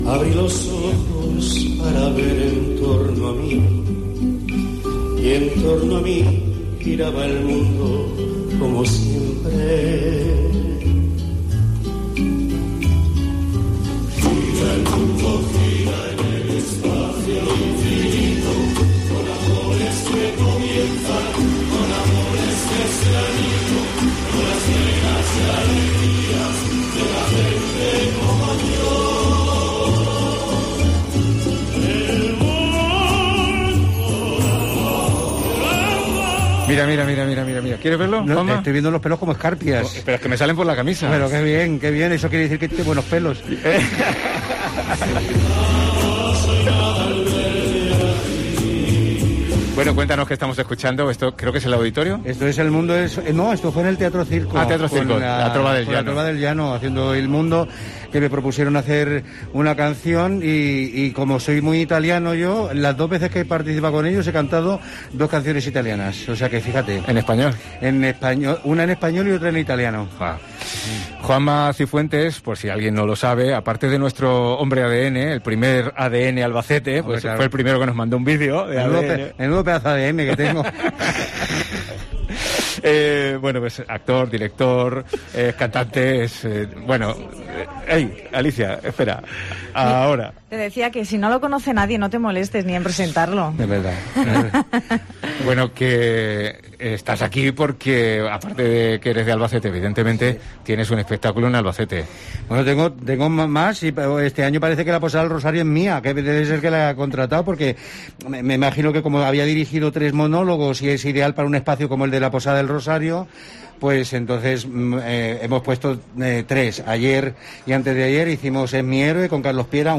ENTREVISTA COPE
Con programación teatral para estos días de Feria en la Posada del Rosario y con proyectos que nos cuenta en esta entrevista divertida y hasta compartida con Serafín Zubiri